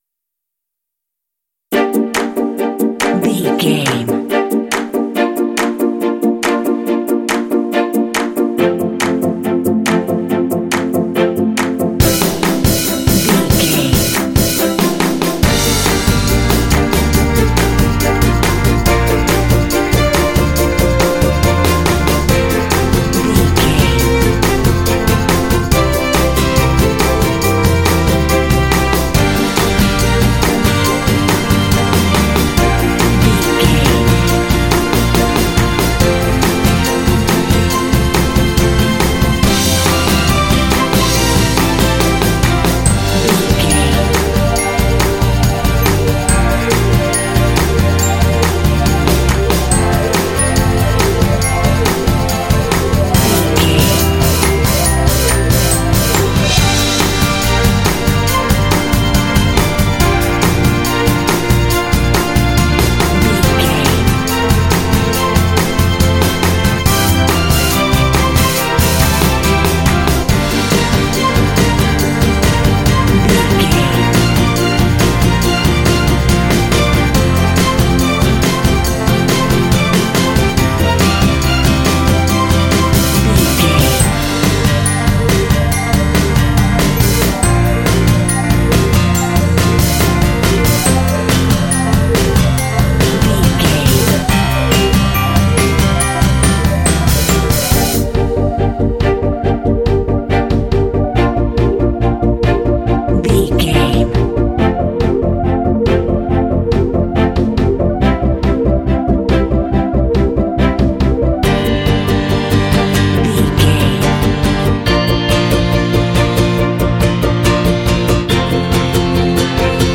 Fast paced
Dorian
Fast
energetic
cheerful/happy
strings
acoustic guitar
bass guitar
drums
synthesiser
piano
symphonic rock
cinematic
alternative rock